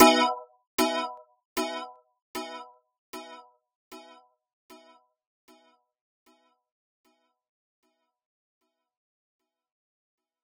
Echoes_Emaj.wav